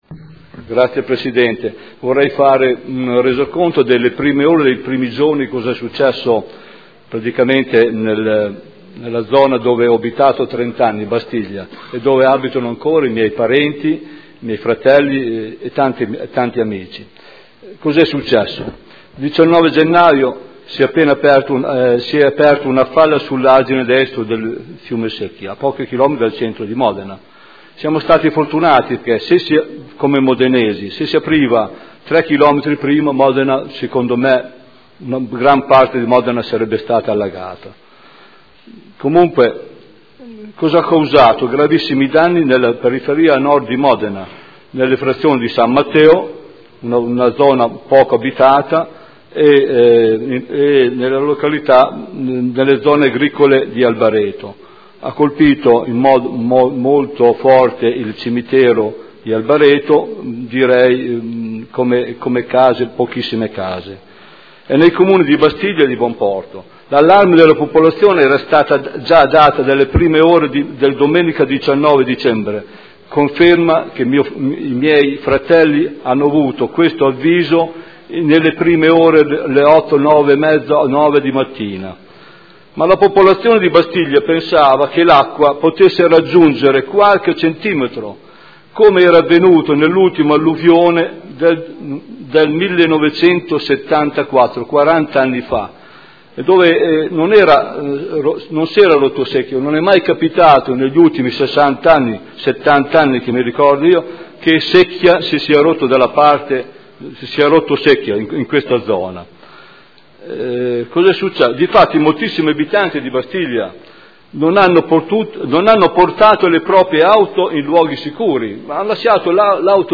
Stefano Goldoni — Sito Audio Consiglio Comunale
Seduta del 30/01/2014. Dibattito su interrogazioni riguardanti l'esondazione del fiume Secchia.